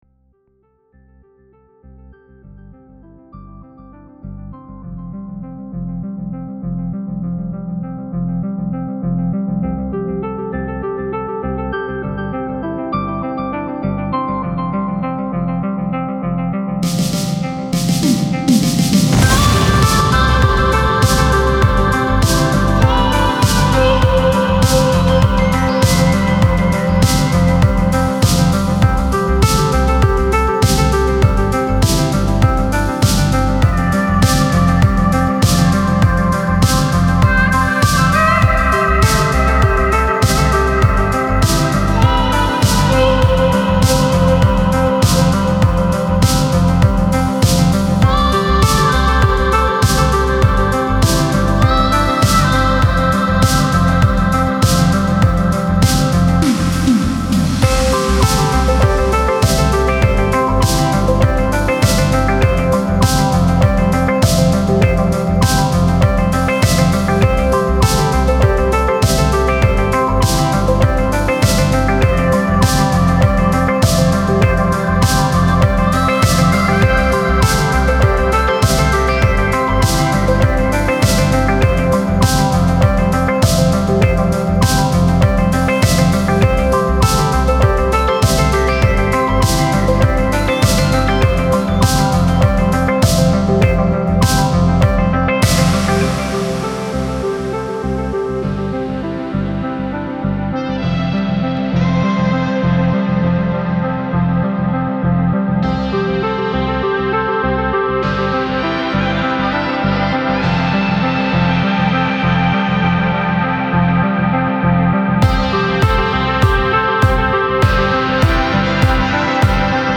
это атмосферная композиция в жанре пост-рок